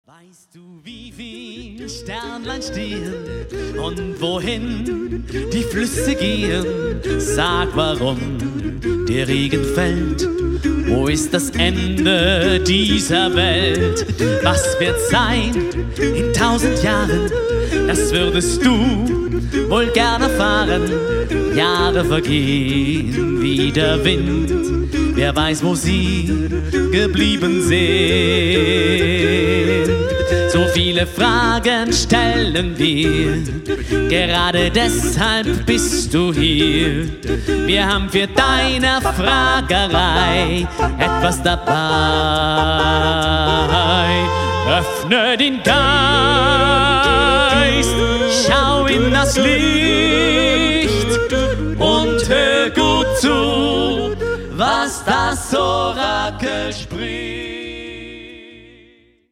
die LIVE-CD zum gleichnamigen Programm